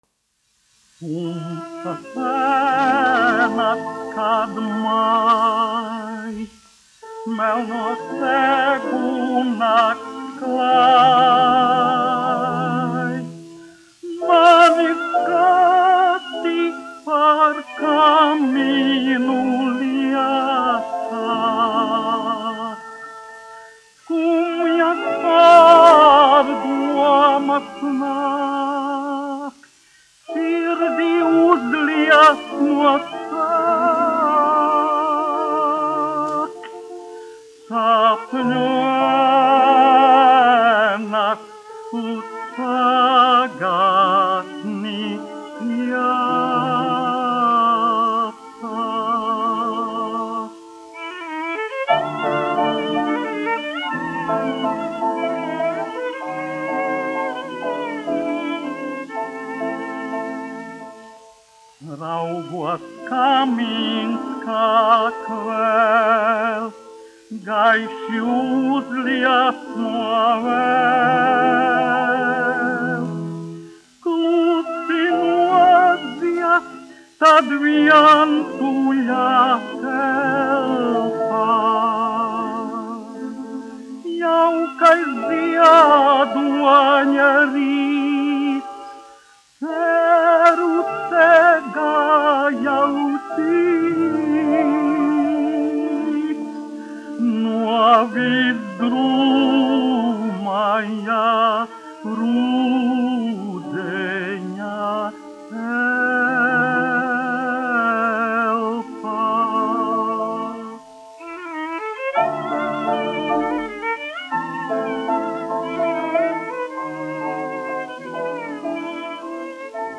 dziedātājs
1 skpl. : analogs, 78 apgr/min, mono ; 25 cm
Krievu dziesmas
Skaņuplate